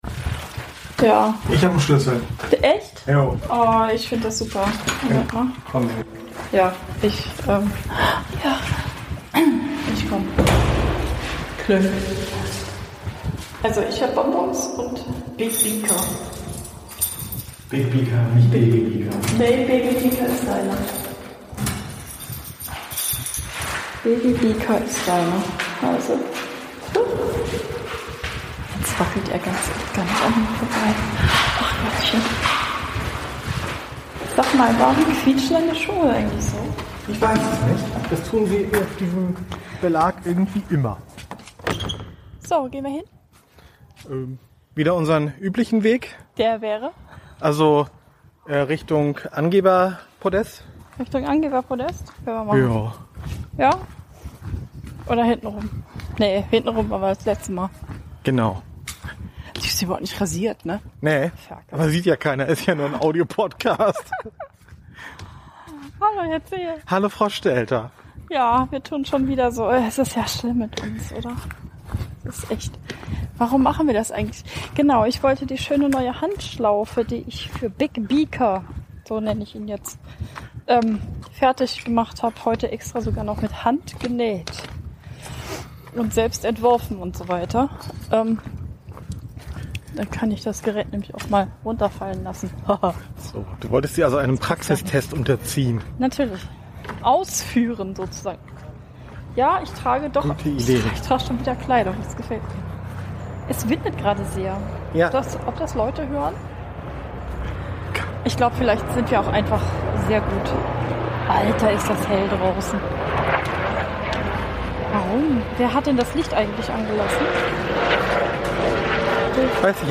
Dieser Podcast wird hauptsächlich Euer rechtes Ohr beschallen, falls Ihr das in Stereo hört.